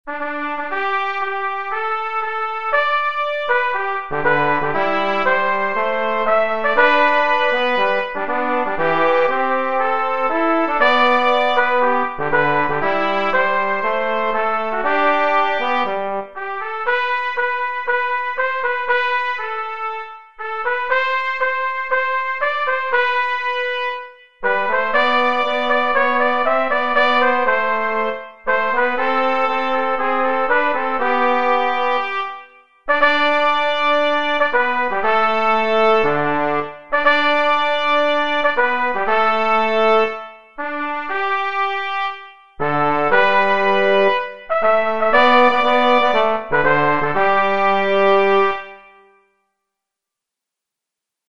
Note that, in each of the three 8-bar sections, a canon is possible, offering considerable added interest.
With a chorus of 8 bars, we would end up with 3 equal sections of 8 bars each, 24 bars in all.
Starting at the fifth bar, the canon is at a 1-bar distance,
then, in the penultimate bar, at a half-bar distance.